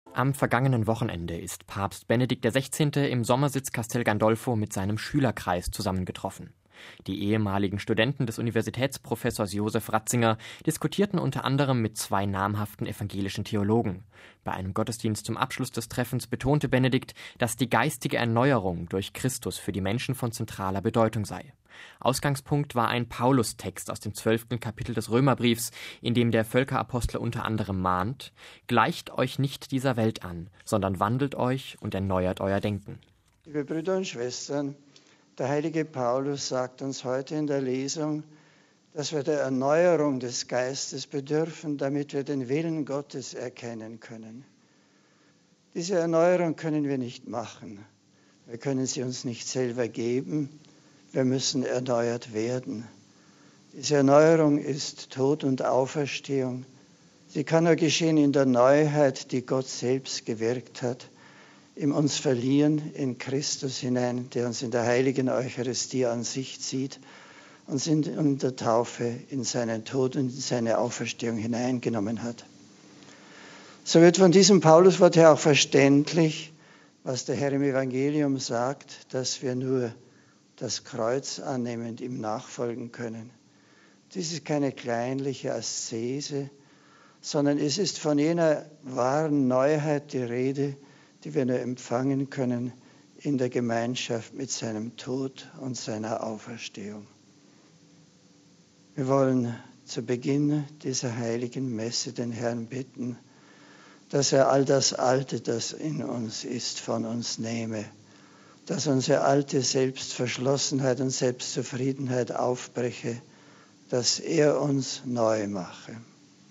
Bei einem Gottesdienst zum Abschluss des Treffens betonte Benedikt, dass die geistige Erneuerung durch Christus für die Menschen von zentraler Bedeutung sei.